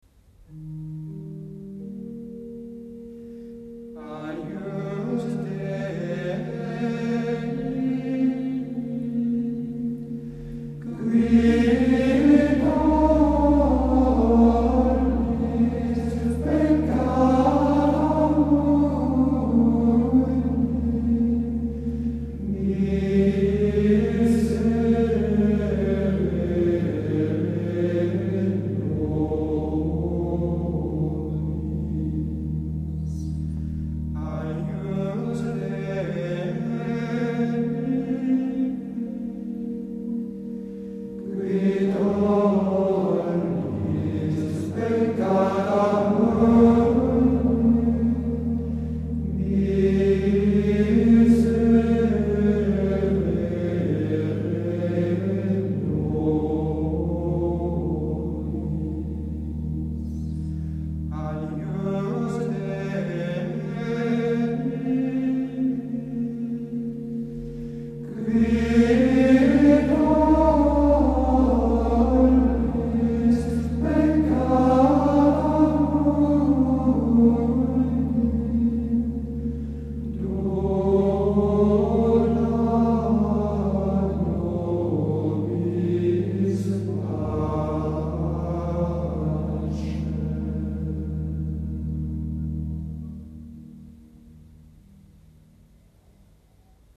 • gloria grégorien sanctus agnus
Cet Agnus Dei du XIIe-XIIIe siècle est répertorié dans plus de 130 sources manuscrites provenant en majorité d’Italie mais aussi d’Allemagne, de France, d’Espagne, des pays de l’est. Il emprunte sa belle mélodie au 5e mode dont les intervalles lumineux tempérés par la tendresse des Sib (on n’entend aucun Si naturel) conviennent très bien à la louange de la Sainte Vierge.
Les trois invocations sont identiques à l’exception des mots qui tollis peccáta mundi qui, dans la seconde invocation, revêtent une forme mélodique plus humble.
La mélodie poursuit son chemin ascendant sur les mots qui tollis, atteignant le sommet de toute la pièce avec le Fa de l’accent de tollis.
Du coup, ce passage est mis en relief par rapport à tout ce qui précédait, et demande une interprétation humble, douce, piano, plus intérieure.